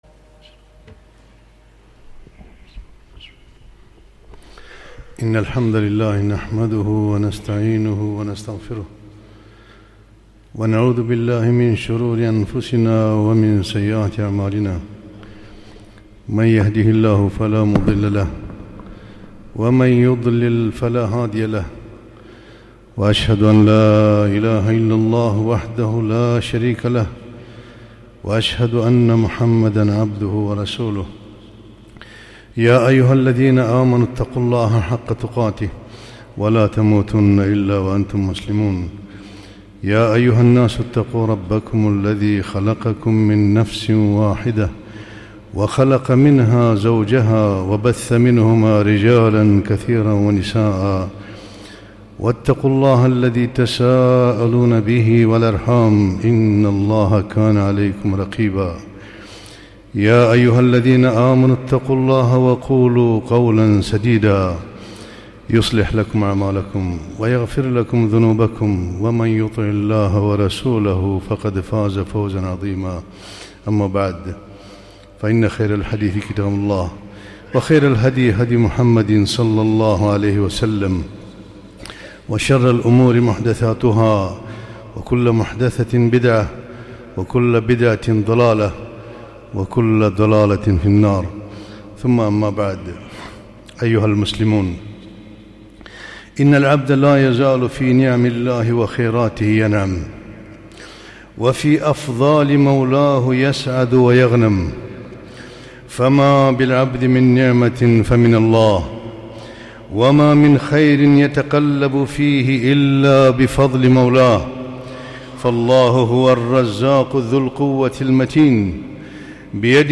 خطبة - اهدنا الصراط المستقيم